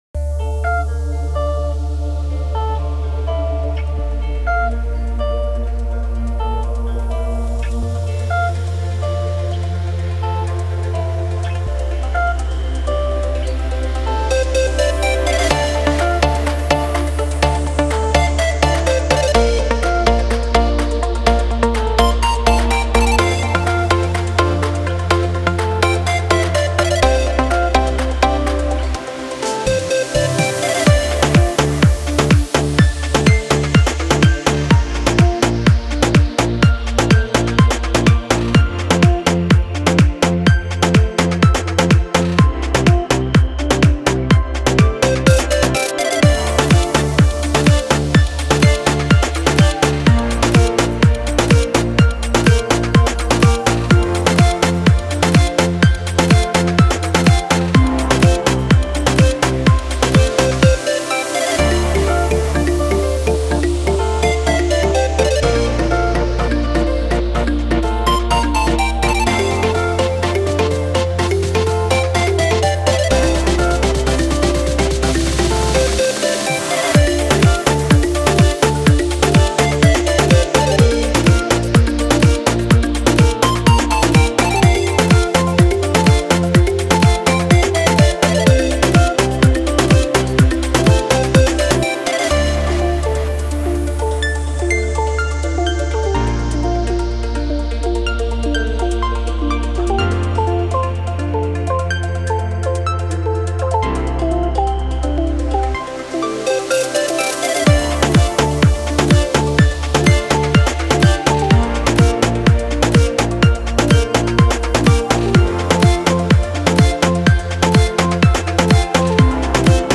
это яркая и ритмичная композиция в жанре этно-электро